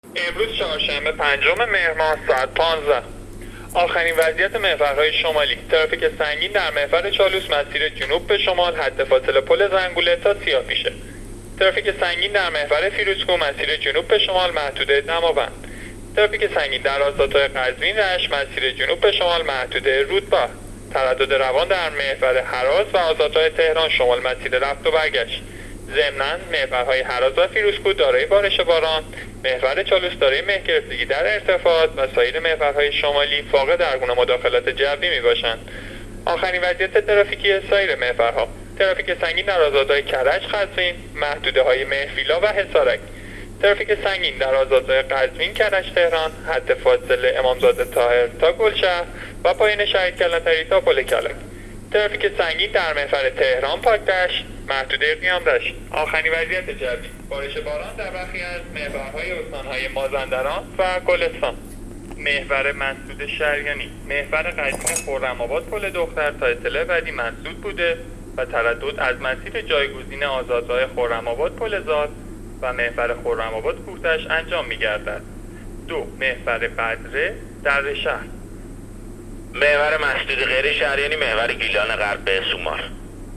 گزارش رادیو اینترنتی از آخرین وضعیت ترافیکی جاده‌ها تا ساعت ۱۵ پنجم مهر؛